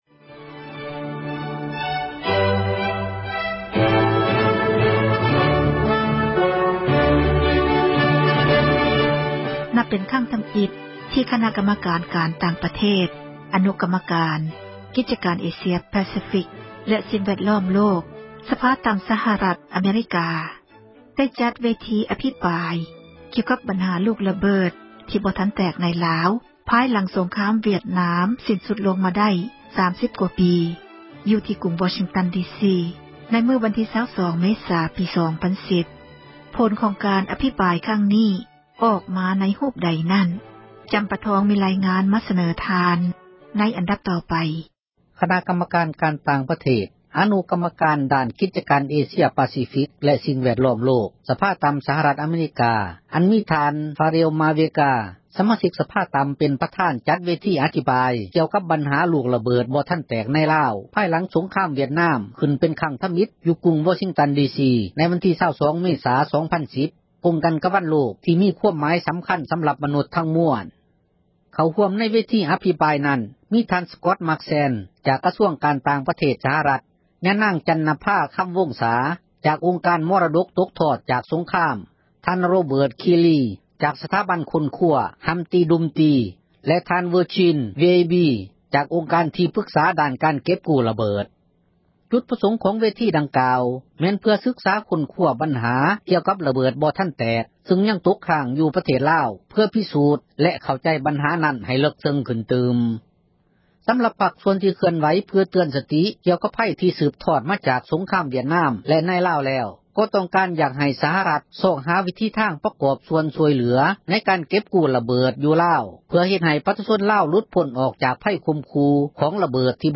ມີລາຍງານມາ ສເນີທ່ານ.